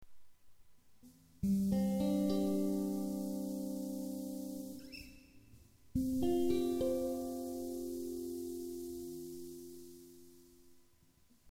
ギターとベースの録音をしてるんですがノイズが酷いんです。
ギター→アンプシミュレータ(pandora)→USBオーディオIF(UA-20)→PC(SONAR LE)
07 オーディオIFの音量大